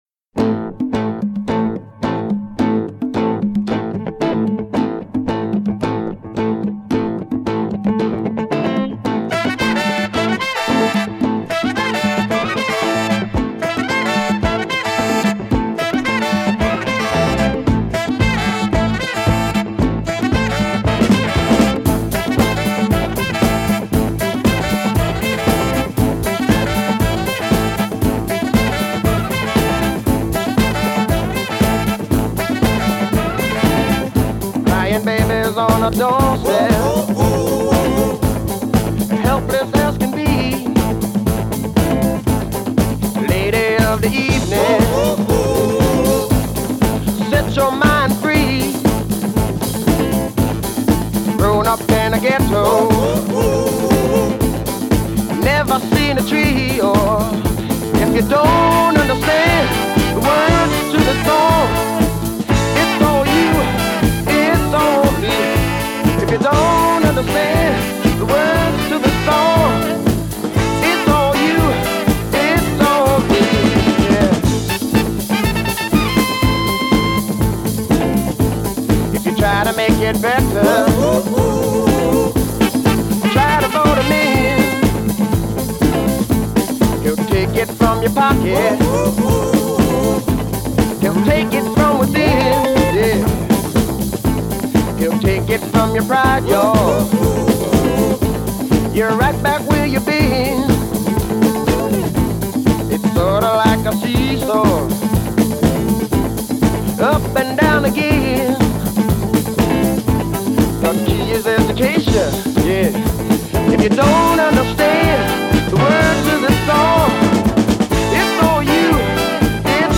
prime slice of 1973 funk